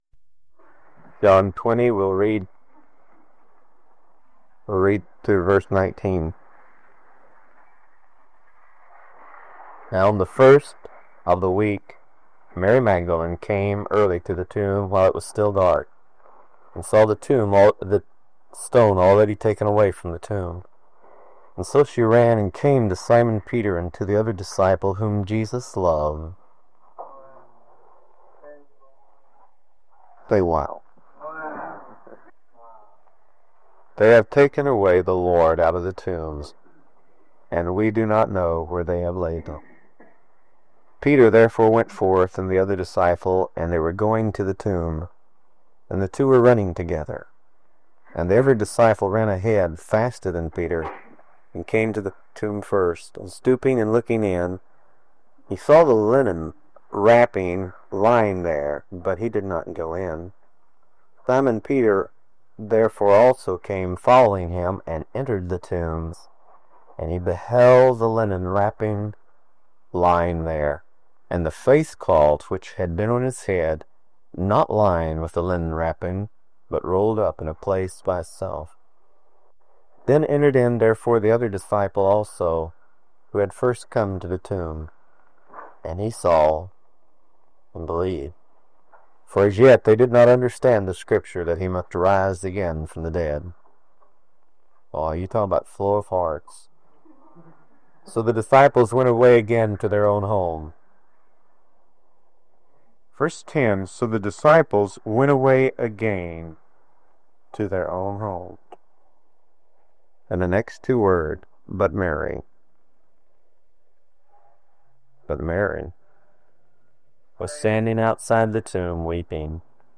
The sermon focuses on the “driving force” of this passion that compelled Christ to the cross, where every enemy—the whole fallen creation—was